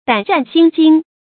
注音：ㄉㄢˇ ㄔㄢˋ ㄒㄧㄣ ㄐㄧㄥ
膽顫心驚的讀法